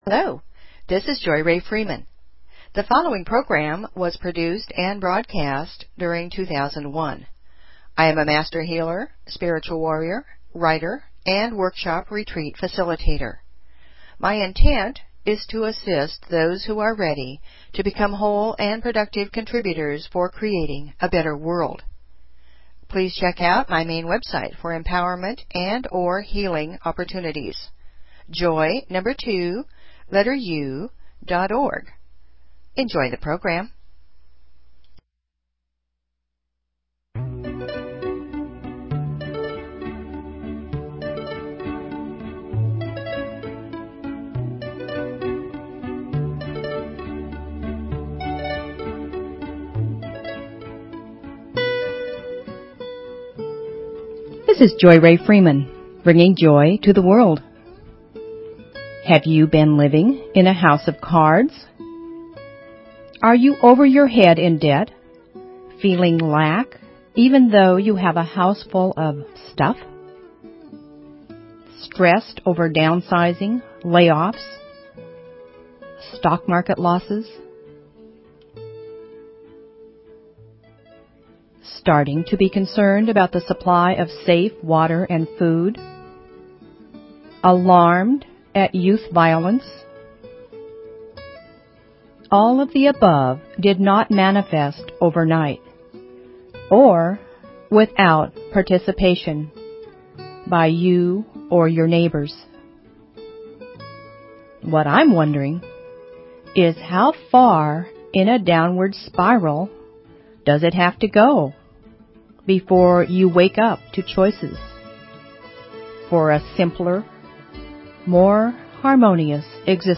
Talk Show Episode, Audio Podcast, Joy_To_The_World and Courtesy of BBS Radio on , show guests , about , categorized as
HOUSE OF CARDS (2001) Music, poetry, affirmations, stories, inspiration . . .